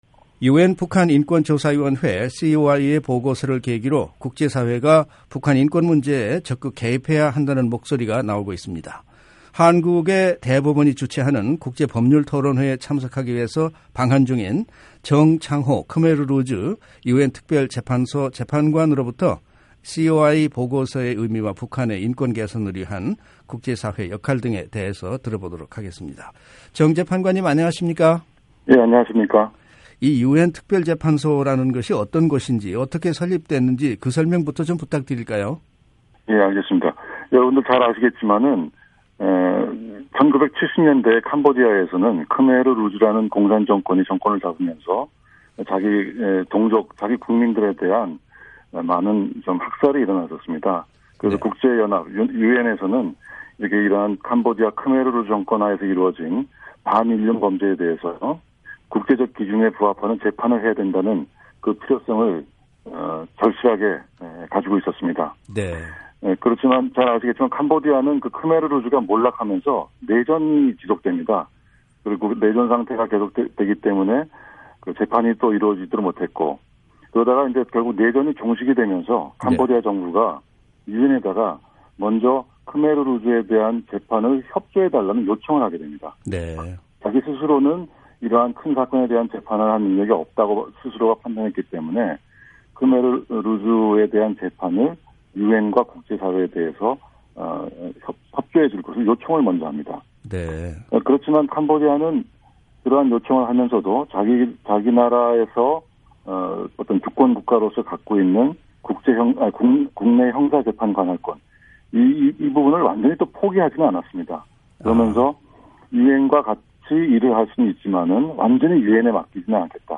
[인터뷰: 크메르루주 유엔특별재판소 정창호 재판관] 북한인권 개선 위한 국제사회 역할